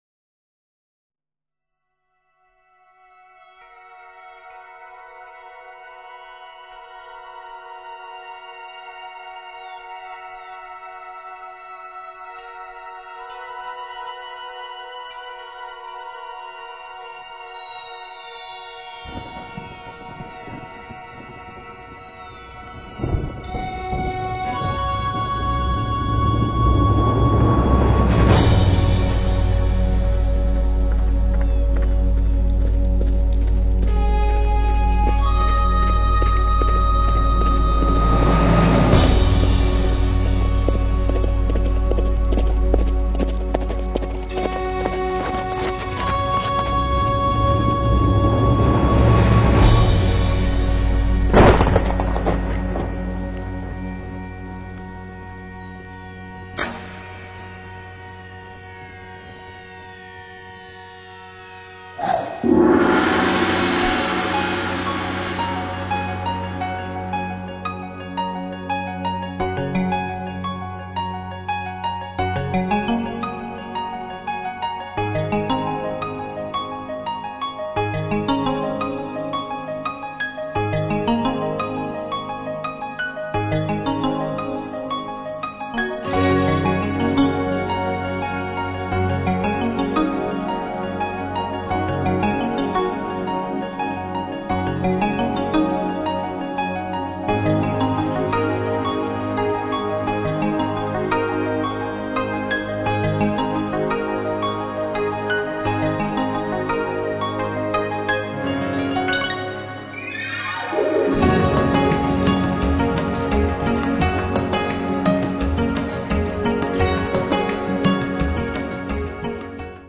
键盘和打击乐像极射手的马蹄，奔驰的节奏感，一如射手追求圆满而永恒的真理。